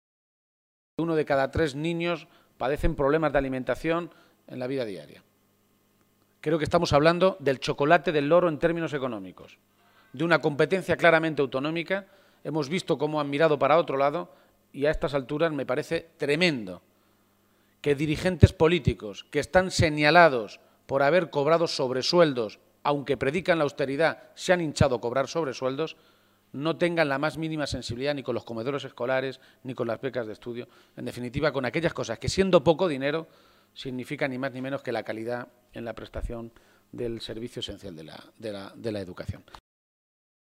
Emiliano García-Page durante la rueda de prensa celebrada en Guadalajara